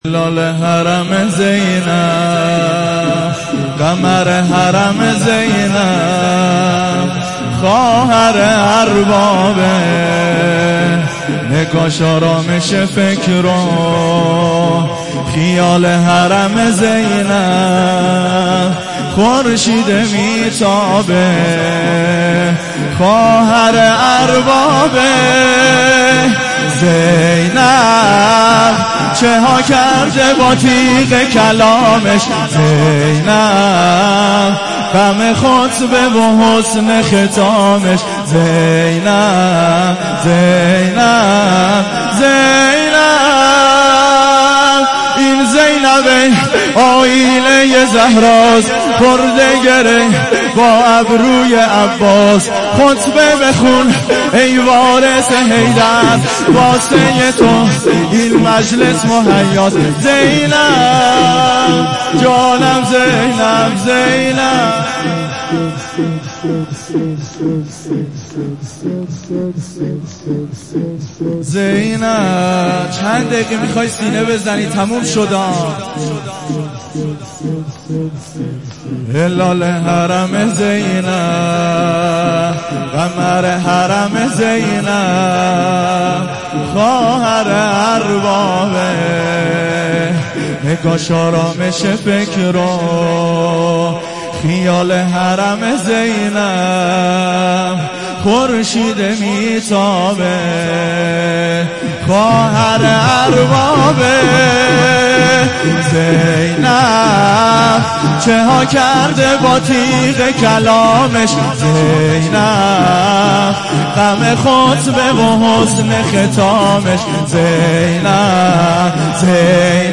شام شهادت امام صادق(ع)